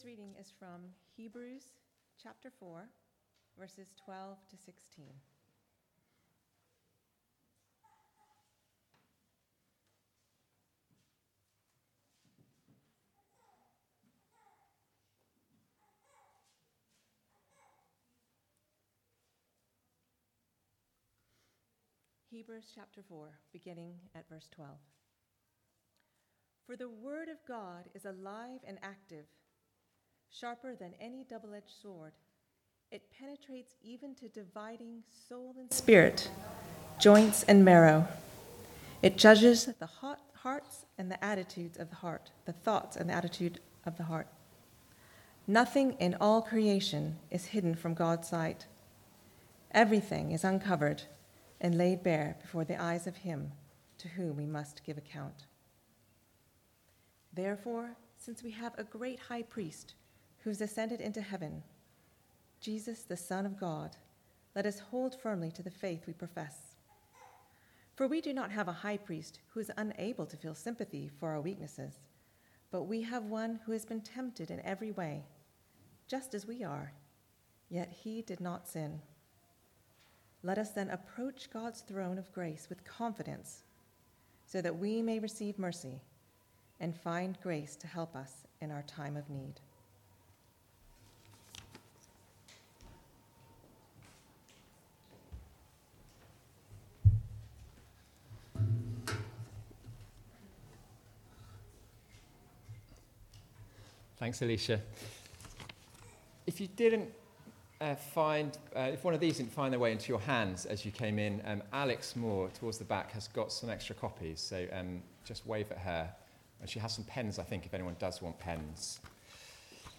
Service Type: Weekly Service at 4pm